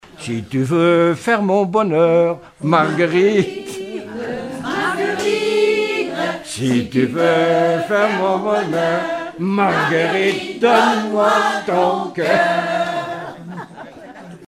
Enfantines - rondes et jeux
Répertoire de chansons populaires et traditionnelles
Pièce musicale inédite